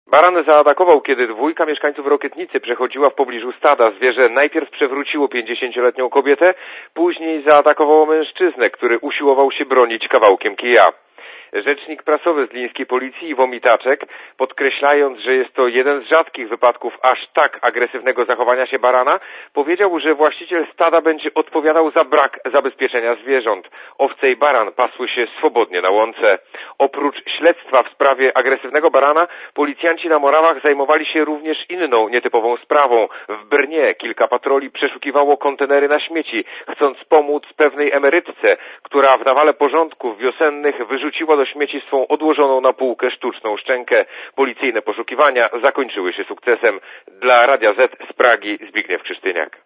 Korespondencja z Pragi (196 KB)